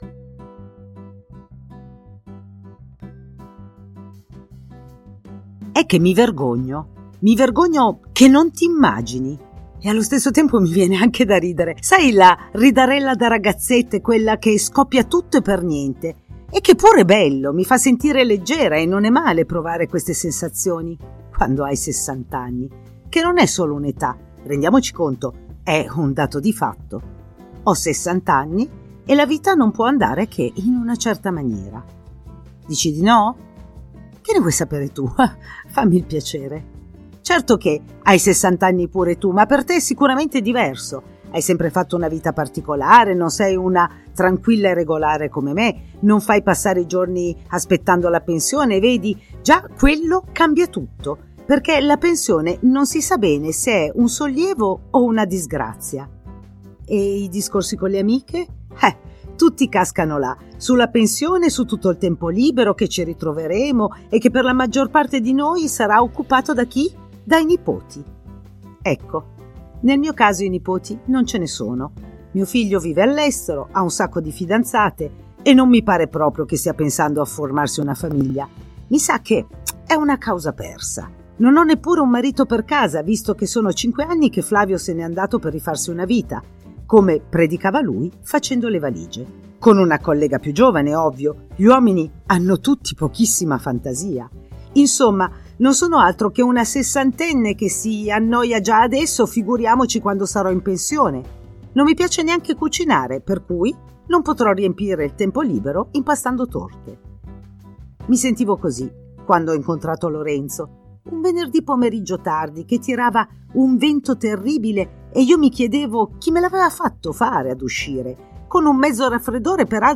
Il podcast della storia vera: parla una donna che si è innamorata a 60 anni.